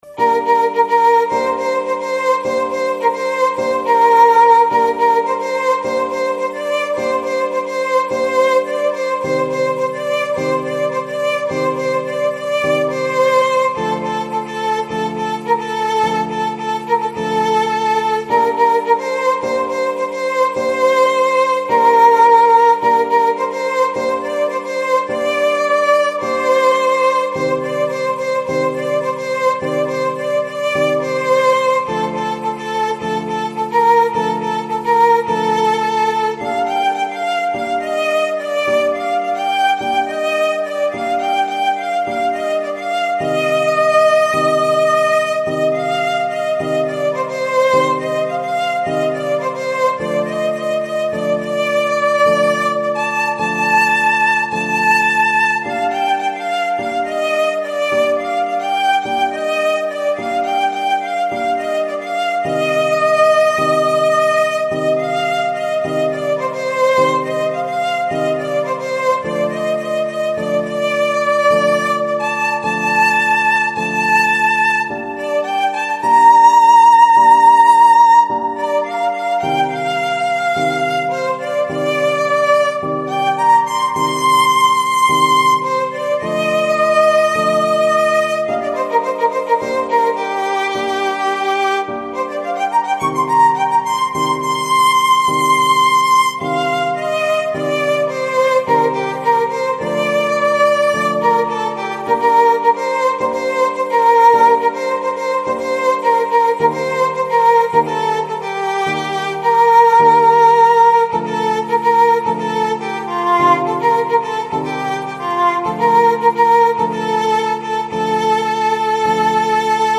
ویولون